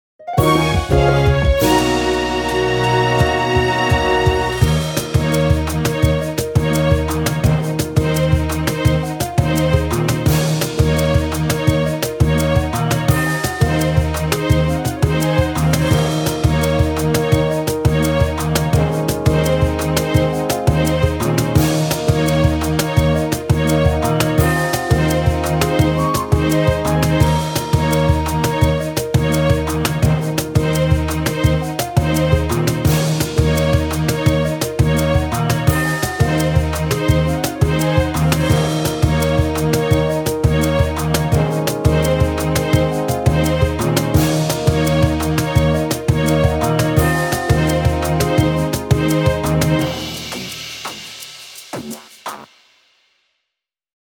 戦闘終了・ステージクリア後のリザルト画面をイメージしました。
ループしません。